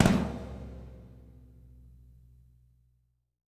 -TOM 2N   -R.wav